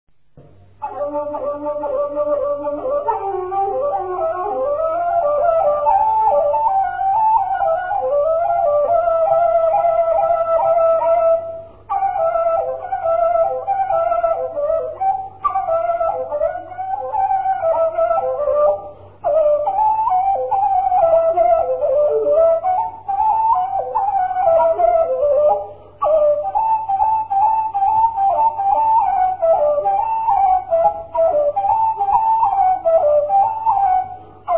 музикална класификация Инструментал
тематика Хороводна (инструментал)
размер Две четвърти
фактура Едногласна
начин на изпълнение Солово изпълнение на кавал
битова функция На хоро
фолклорна област Североизточна България
място на записа Добрина
начин на записване Магнетофонна лента